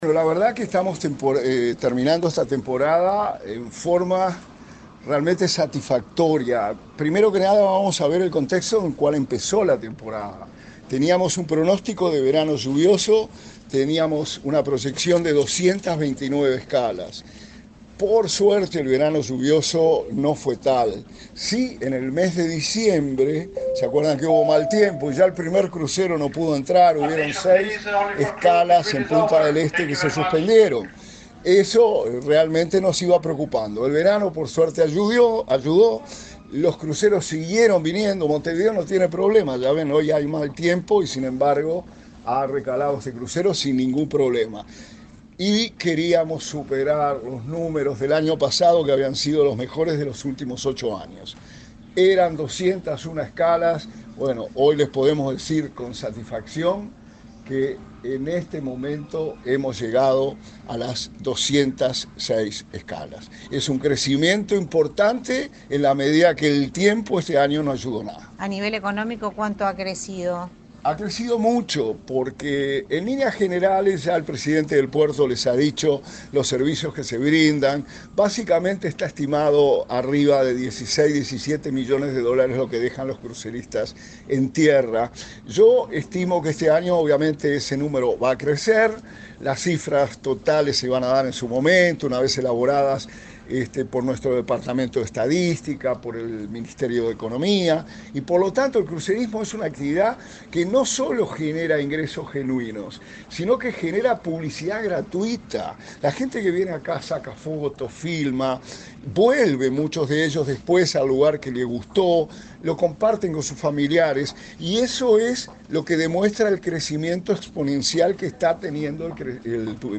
Declaraciones del subsecretario de Turismo, Remo Monzeglio
Este viernes 29 en el puerto de Montevideo, el subsecretario de Turismo, Remo Monzeglio, informó a la prensa acerca de los resultados de la temporada